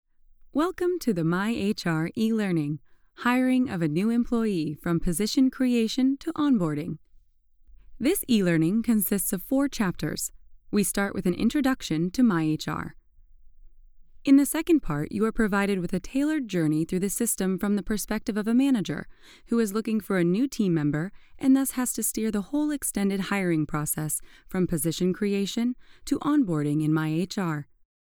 Inglés (Americano)
Joven, Cool, Versátil, Seguro, Cálida
E-learning